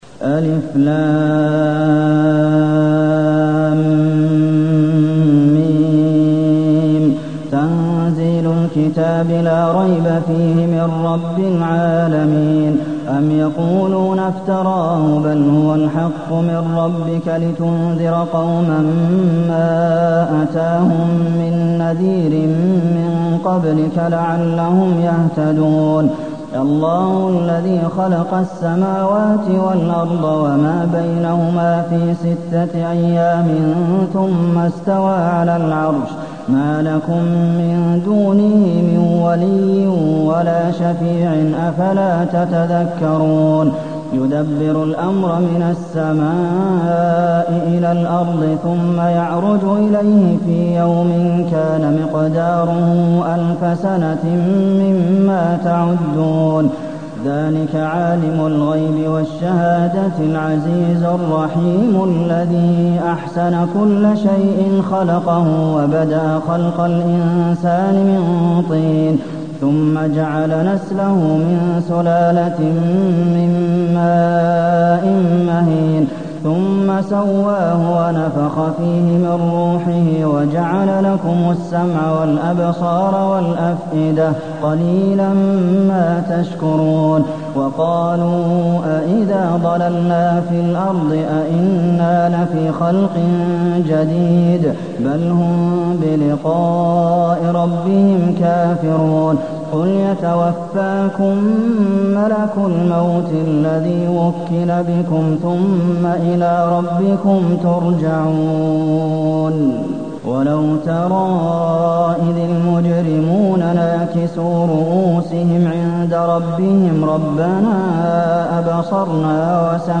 المكان: المسجد النبوي السجدة